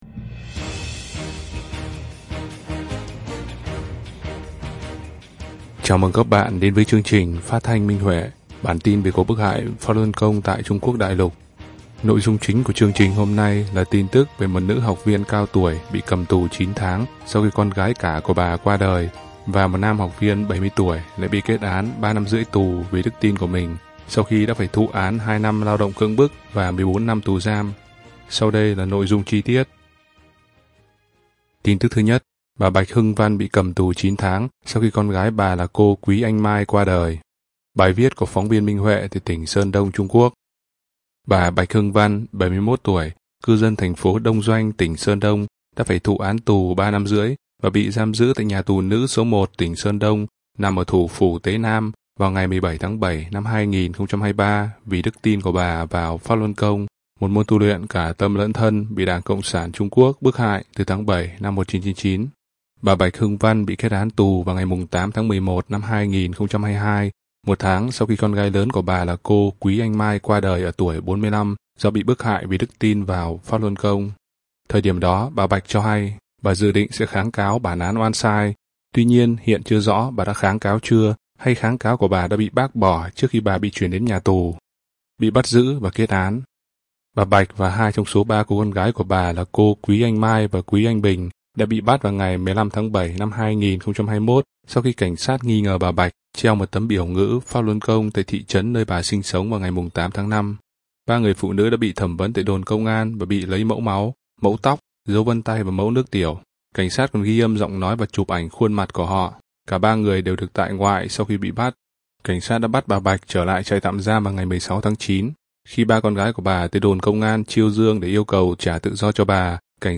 Chào mừng các bạn đến với chương trình phát thanh Minh Huệ.